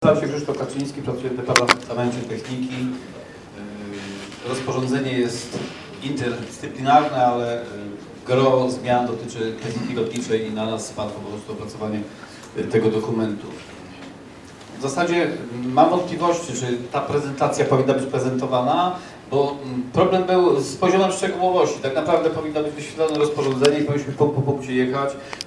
Relacja ze spotkania: